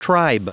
Prononciation du mot tribe en anglais (fichier audio)
Prononciation du mot : tribe